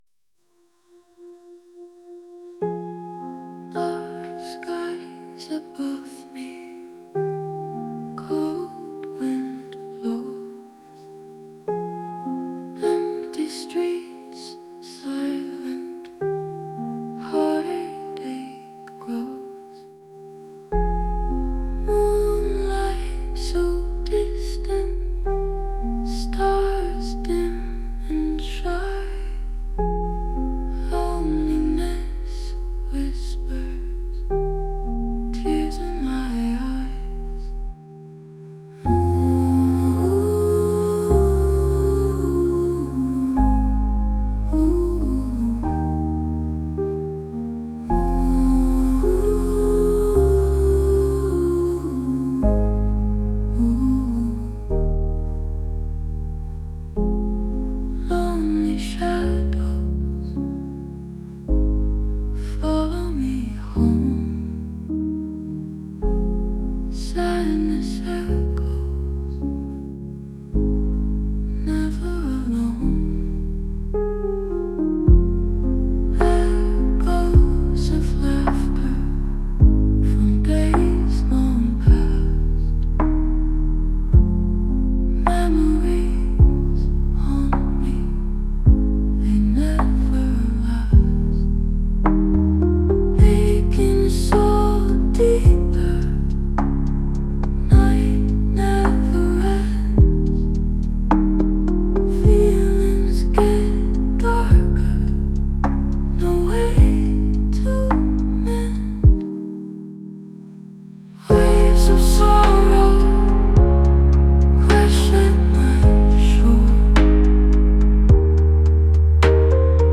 AI MUSIC / SAD AMBIENT
AI Rock
moody melancholic ambient